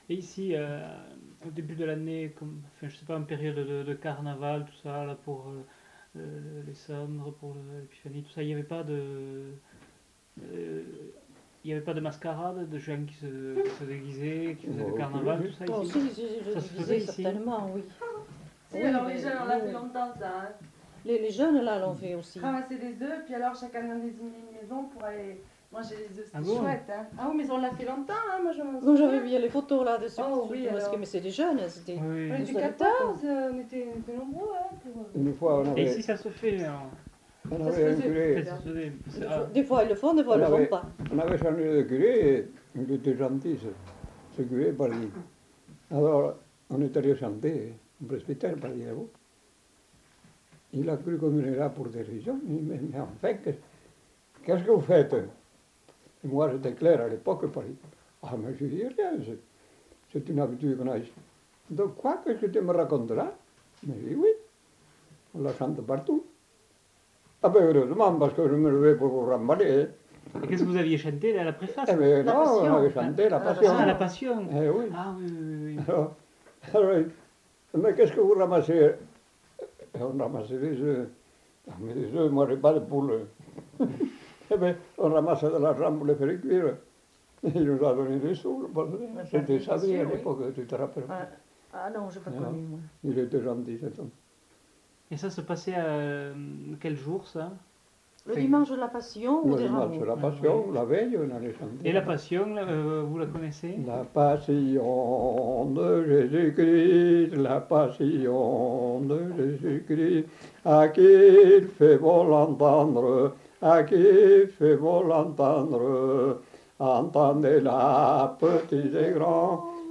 Lieu : Lacroix-Barrez
Genre : chant
Effectif : 2
Type de voix : voix d'homme ; voix de femme
Production du son : chanté